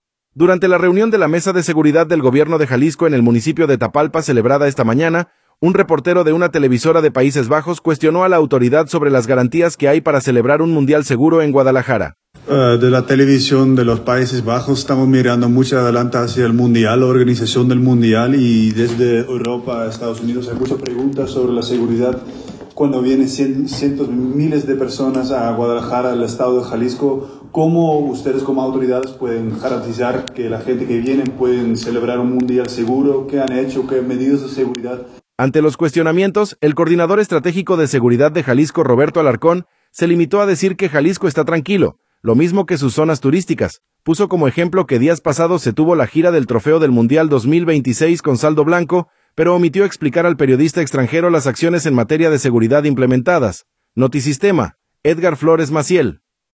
Durante la reunión de la Mesa de Seguridad del gobierno de Jalisco en el municipio de Tapalpa celebrada esta mañana, un reportero de una televisora de Países Bajos cuestionó a la autoridad sobre las garantías que hay para celebrar un mundial seguro […]